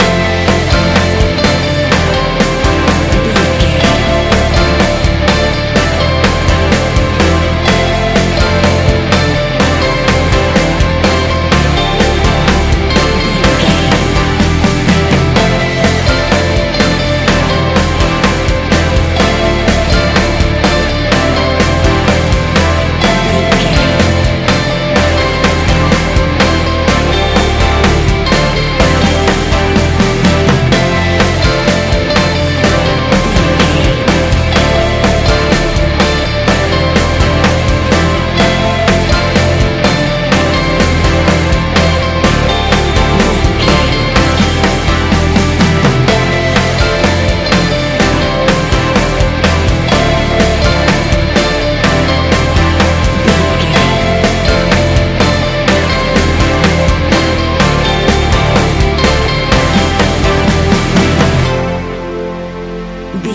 Ionian/Major
D
ambient
electronic
new age
chill out
downtempo
synth
pads
drone